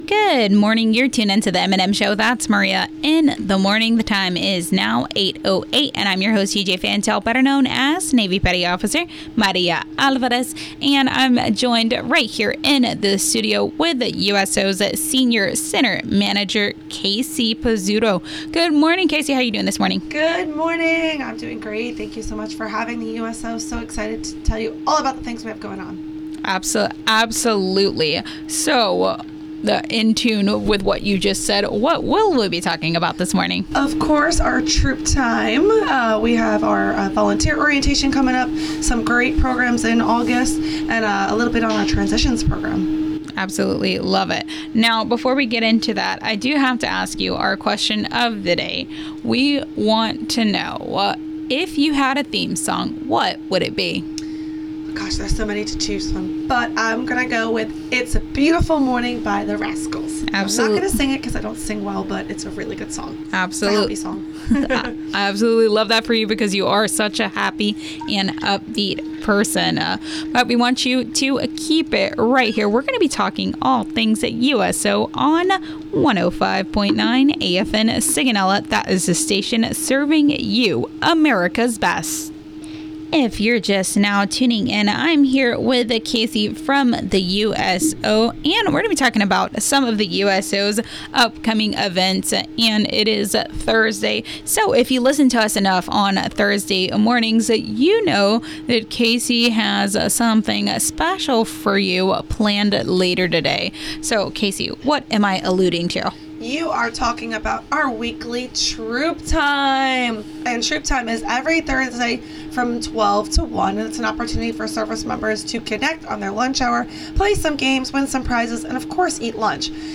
Morning Show Interview